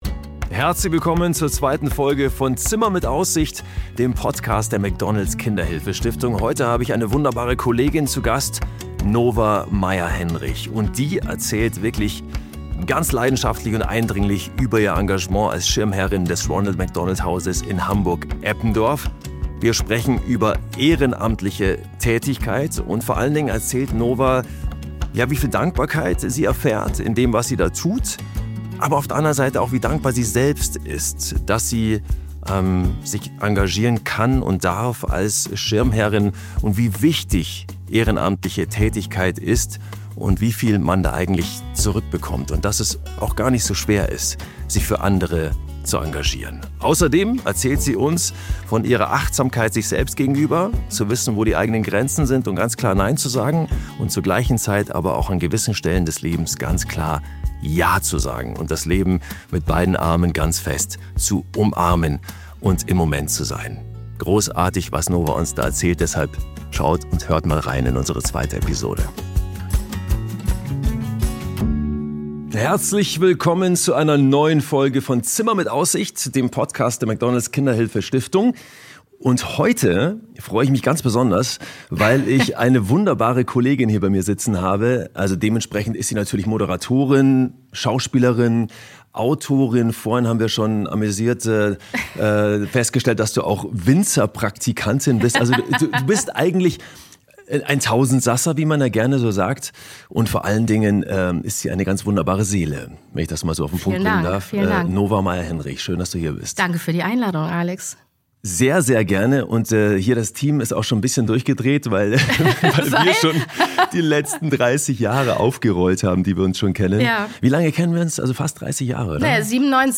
Darum geht’s in dieser Folge: Moderator Alexander Mazza spricht mit Schauspielerin, Moderatorin und Autorin Nova Meierhenrich über ihr langjähriges Engagement als Schirmherrin im Ronald McDonald Haus Hamburg Eppendorf.